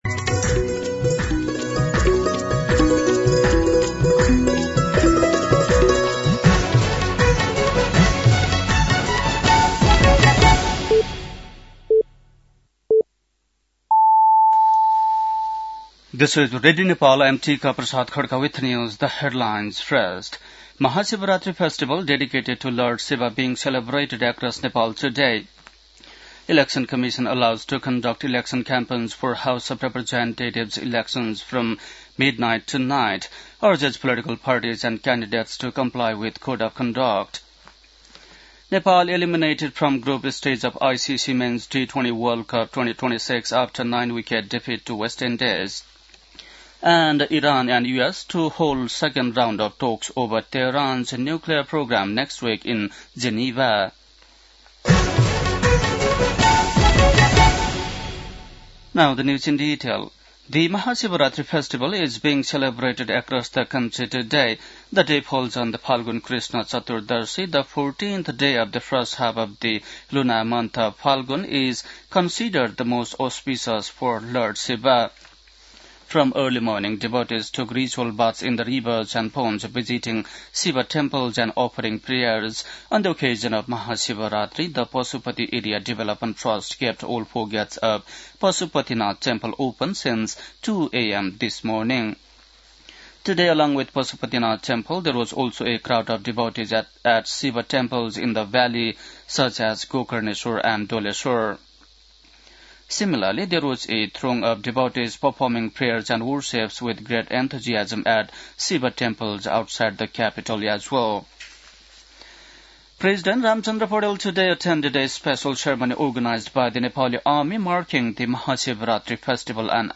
बेलुकी ८ बजेको अङ्ग्रेजी समाचार : ३ फागुन , २०८२
8-pm-news-1-1.mp3